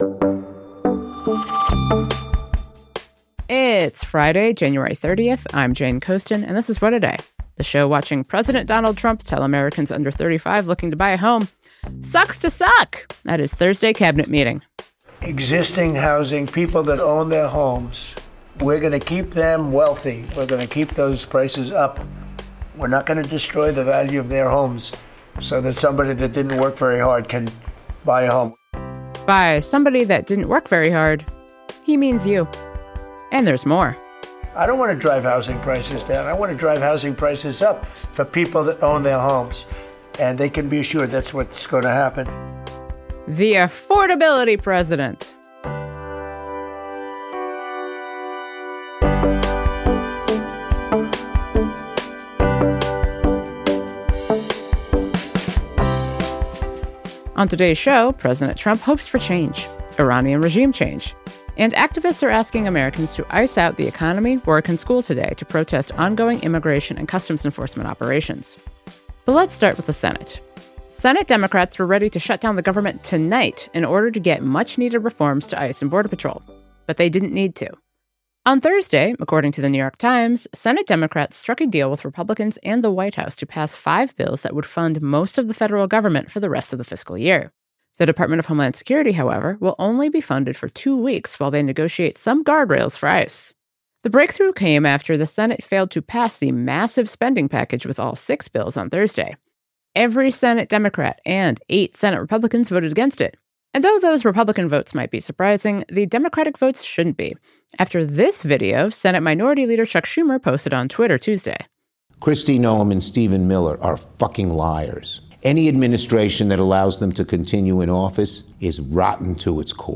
To find out, we spoke with Virginia Democratic Senator Tim Kaine on Thursday just before Senate Democrats announced their deal.